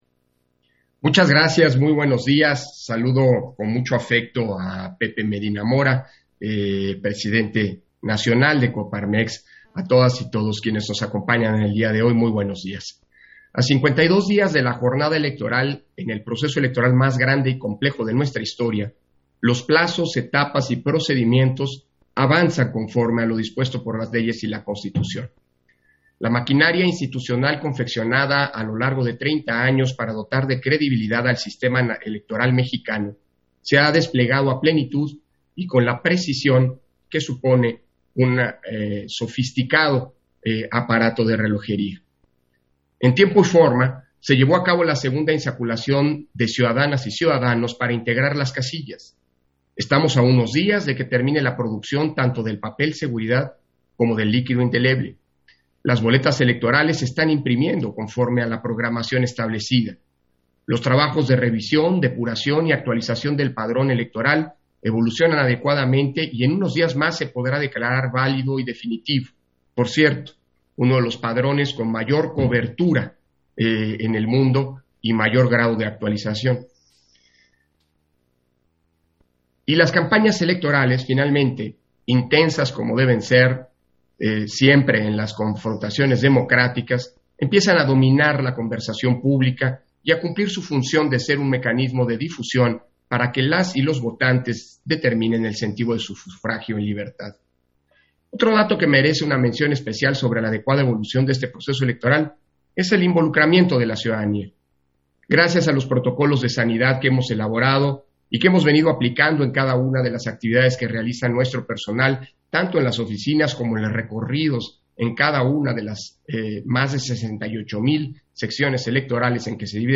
Intervención de Lorenzo Córdova, durante la firma de Convenio de Colaboración, INE-COPARMEX